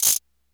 DrShake8.wav